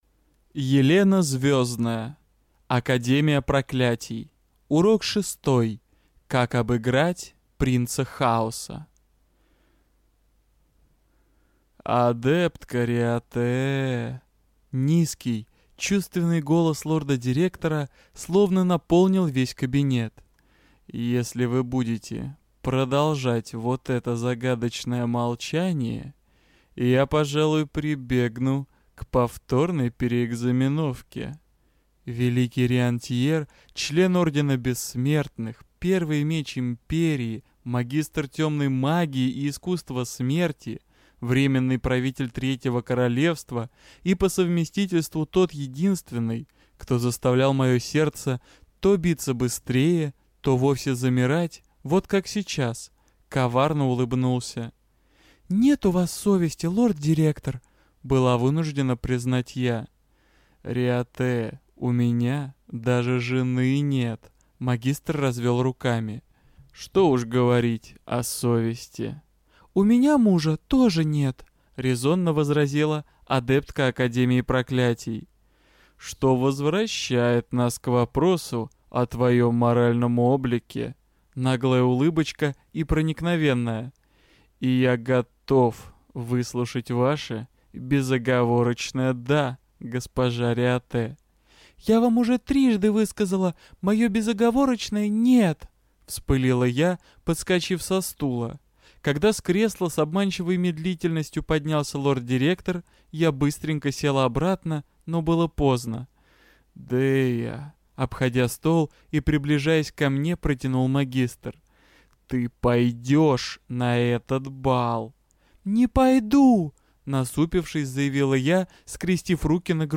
Аудиокнига Урок шестой: Как обыграть принца Хаоса | Библиотека аудиокниг